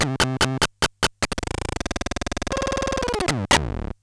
Glitch FX 46.wav